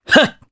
Roi-Vox_Attack1_jp.wav